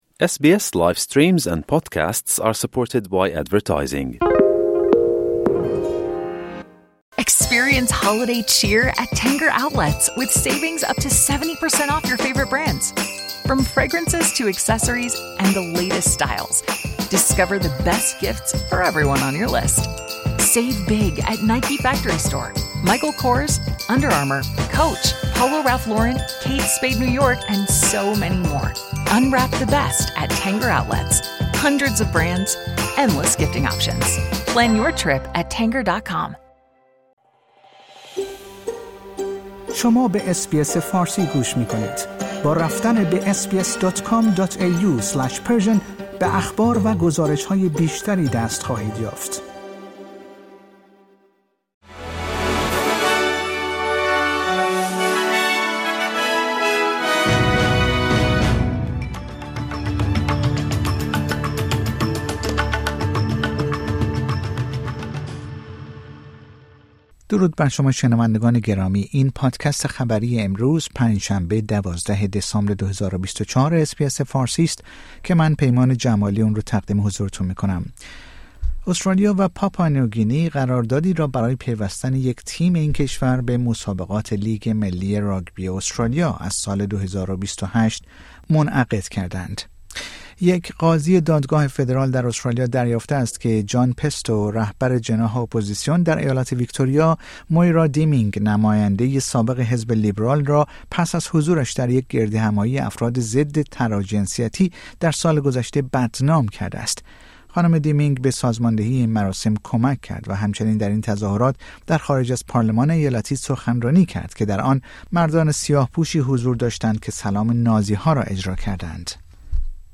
در این پادکست خبری مهمترین اخبار استرالیا در روز پنج شنبه ۱۲ دسامبر ۲۰۲۴ ارائه شده است.